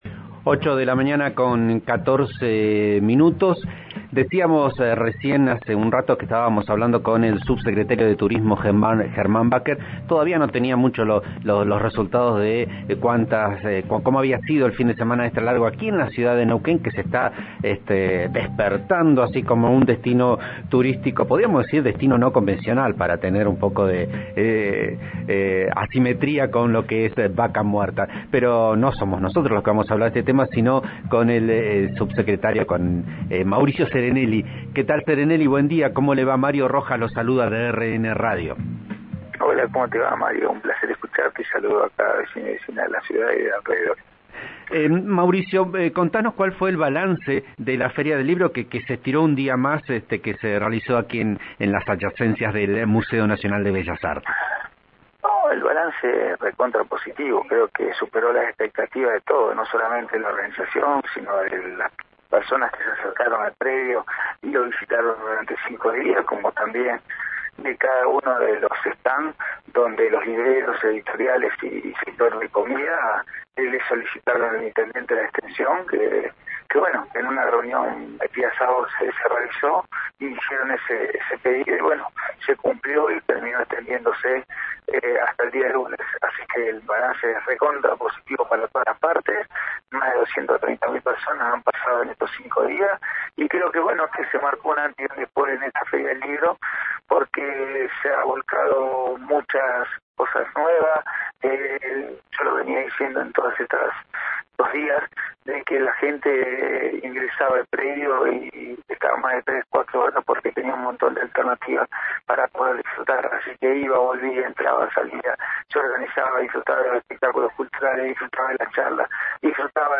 Escuchá la entrevista a Mauricio Serenelli, secretario de Cultura, Deportes y Actividad Física de la Municipalidad de Neuquén en Vos A Diario, por RN RADIO (89.3):